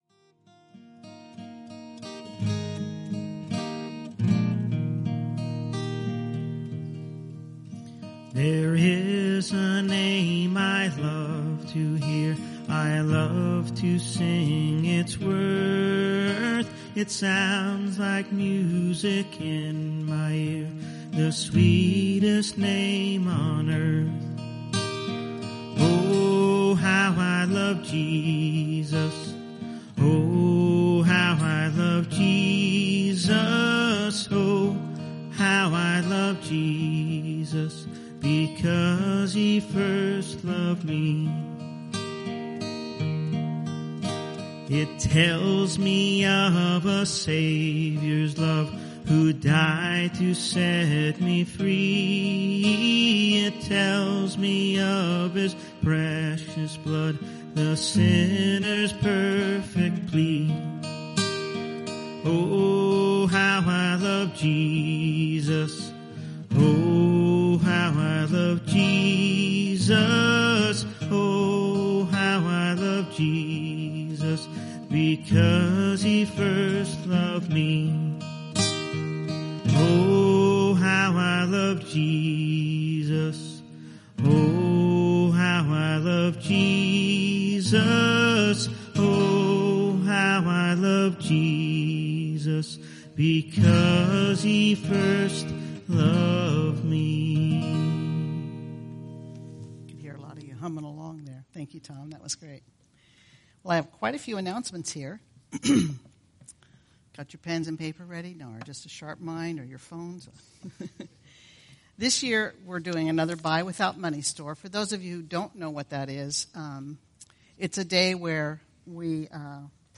Teachings | Teachings